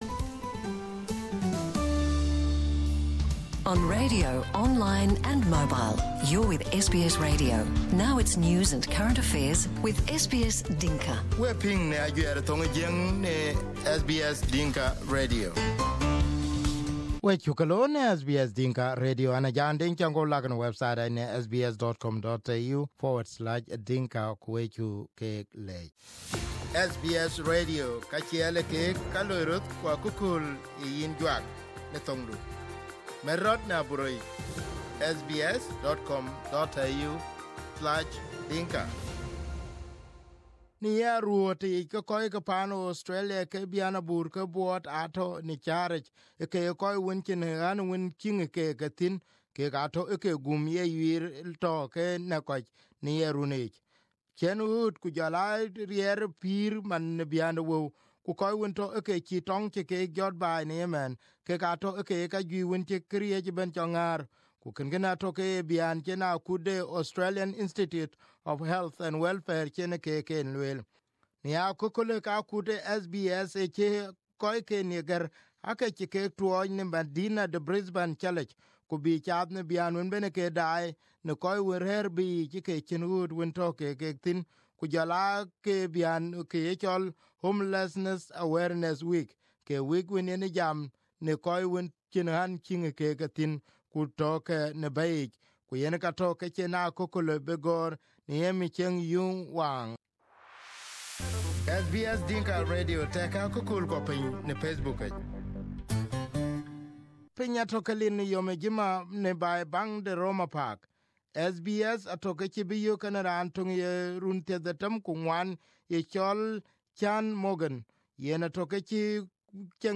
At least 100,000 Australians are living homeless this winter. Housing, financial difficulties and domestic violence are the top three causes of homelessness, according to the Australian Institute of Health and Welfare. SBS takes you to an inner-city park in Brisbane to explore what it means to be homeless during Homelessness Awareness Week.